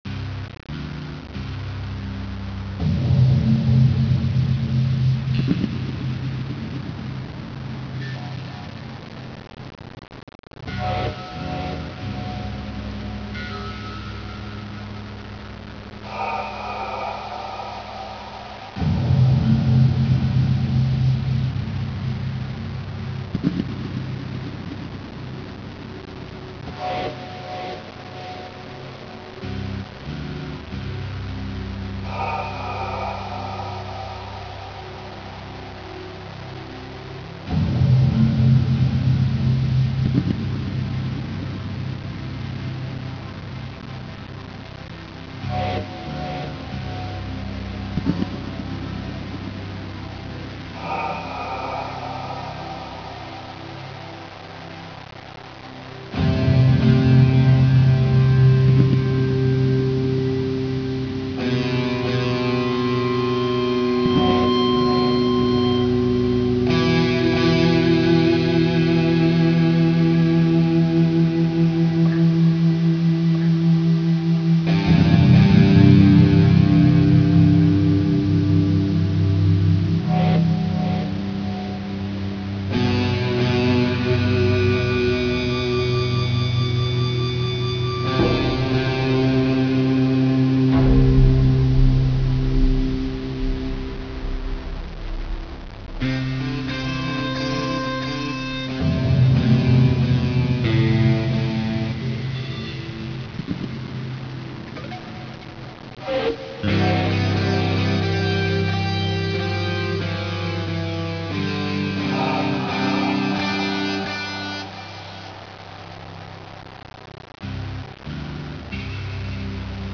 elevamb.wav